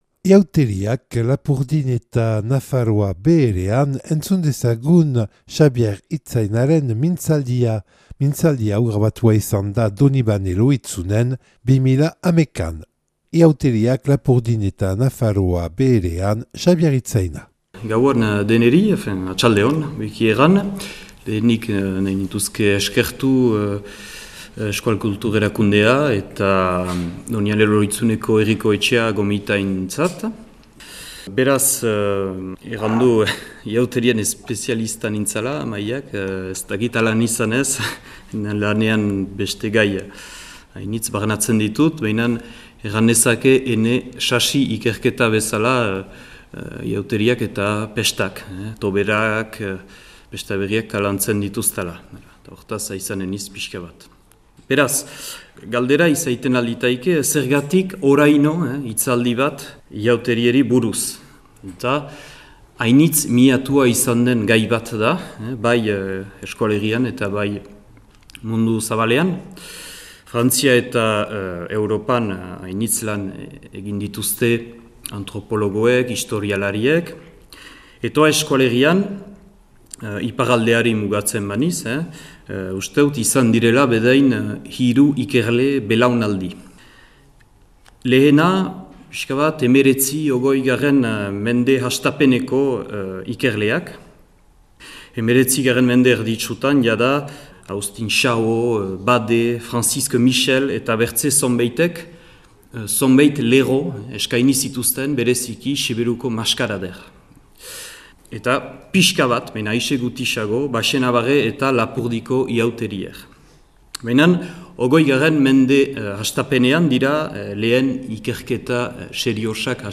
mintzaldia